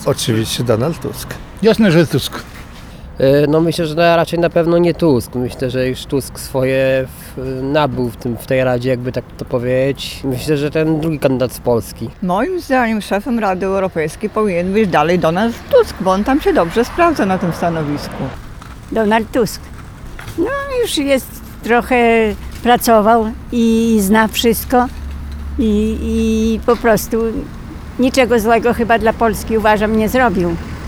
Donalda Tuska typują na szefa Rady Europejskiej osoby, które w czwartek (09.03) spotkaliśmy na ulicach Suwałk.
sonda.mp3